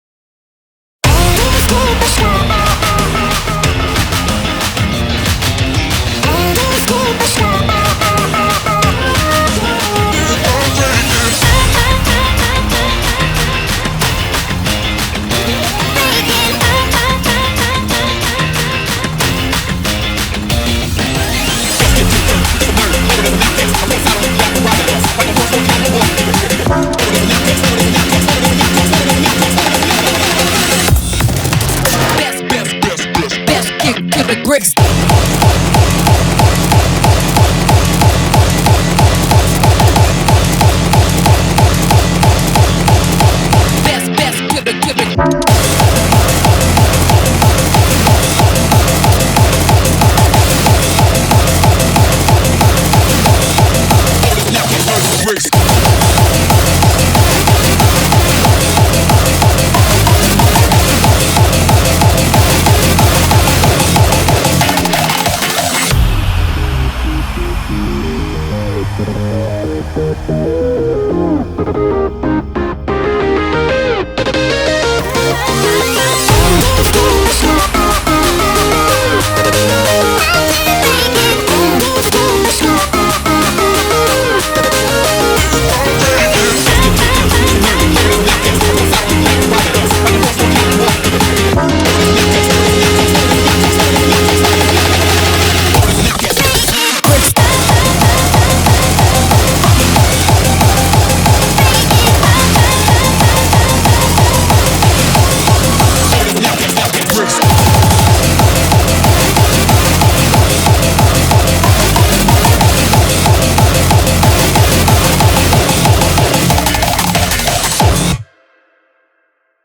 BPM185
Audio QualityPerfect (High Quality)
Commentaires[IRREGULAR OMNIBUS HARDCORE]